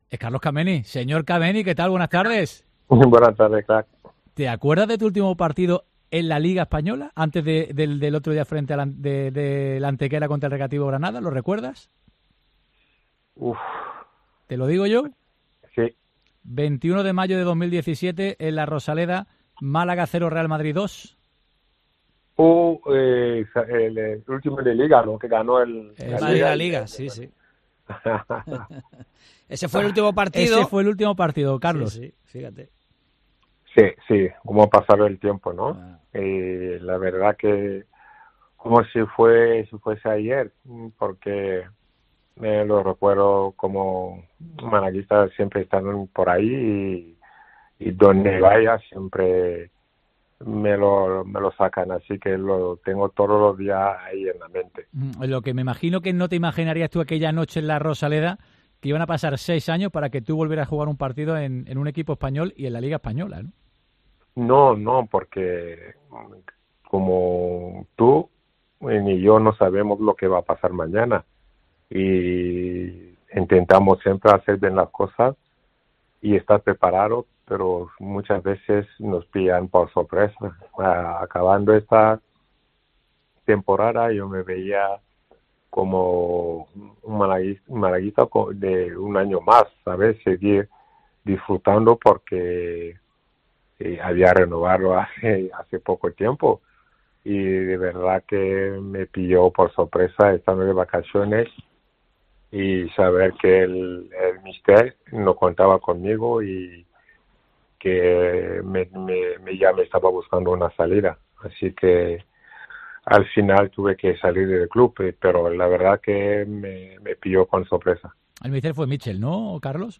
Escucha aquí la entrevista con el portero camerunés Carlos Kameni en COPE Málaga